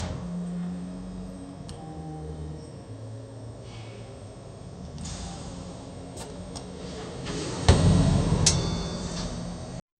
Tesla Cybertruck showroom light indoor ambiance, soft background music, distant voices, footsteps on tile, gentle hum of AC, faint electric motor sound, car door closing softly. 0:10 Created Jul 2, 2025 1:50 PM
tesla-cybertruck-showroom-u6sscwnf.wav